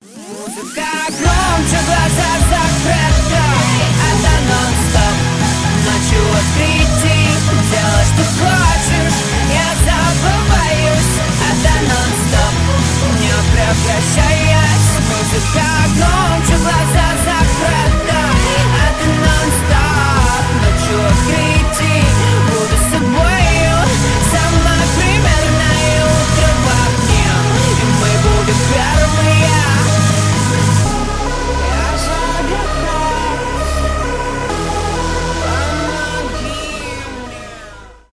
dance rock
поп-панк , electronic rock